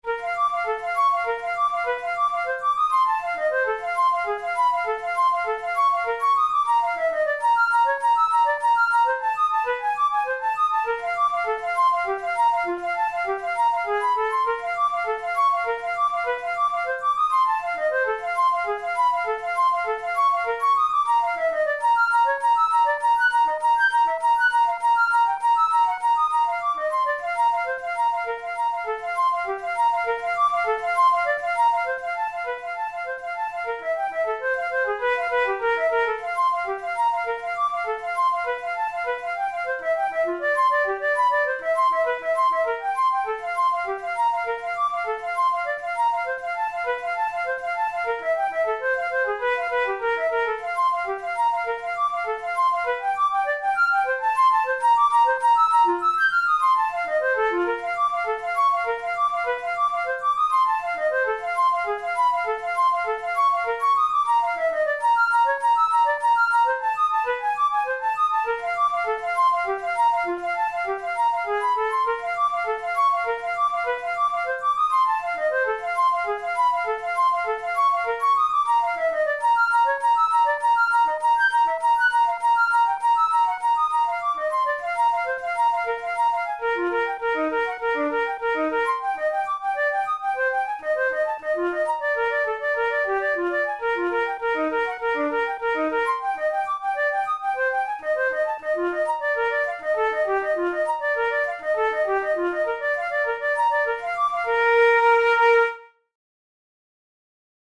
InstrumentationFlute solo
KeyB-flat major
RangeD4–G6
Time signature3/4
Tempo100 BPM
Etudes, Romantic, Written for Flute